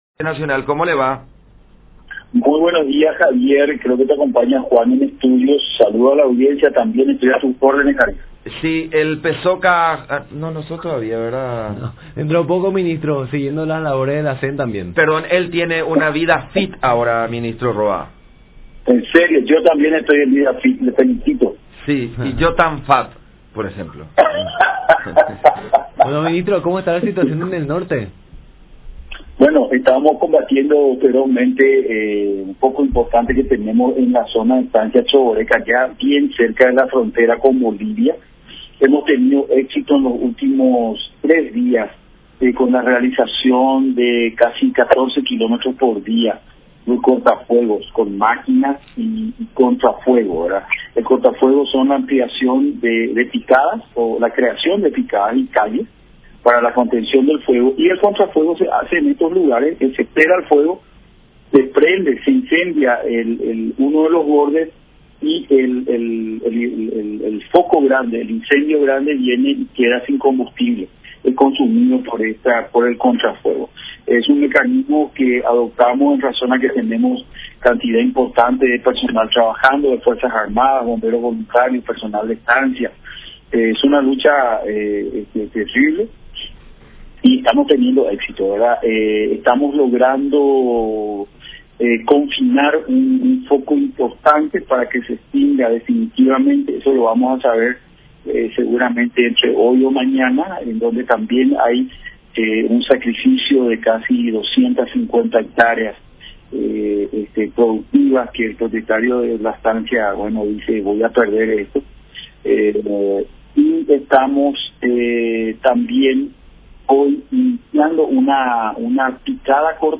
“Hemos tenido éxito en los últimos tres días en la estancia Chovoreca, con la realización de casi 14 kilómetros de cortafuegos por día”, resaltó Roa en contacto con La Unión.